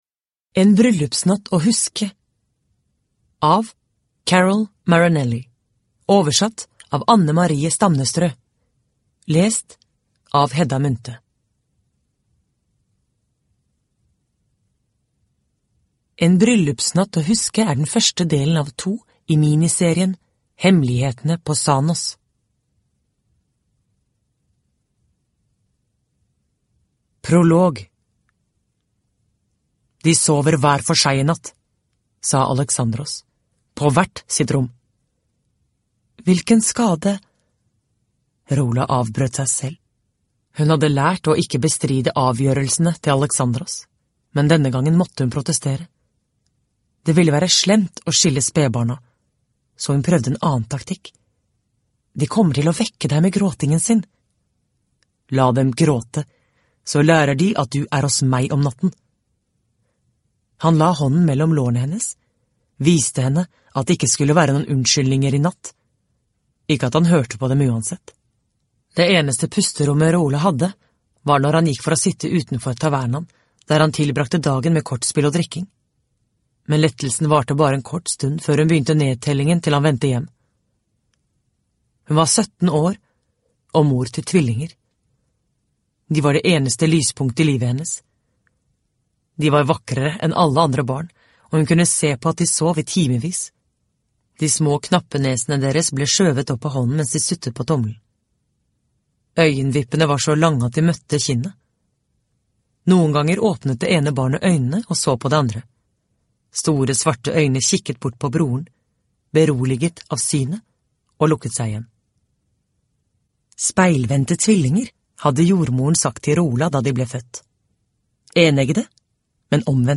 En bryllupsnatt å huske – Ljudbok – Laddas ner